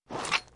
WAV · 98 KB · 立體聲 (2ch)